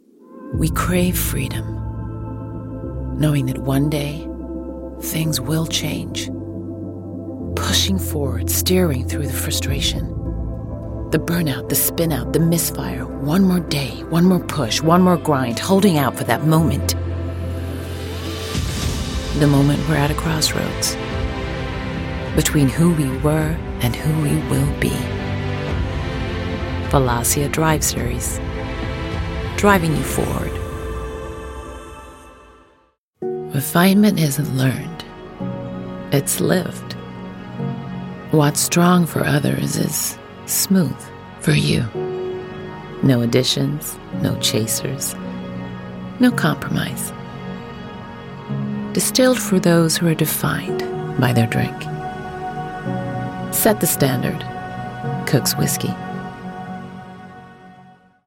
standard us | natural
Victoria_Ekanoye_USAccent.mp3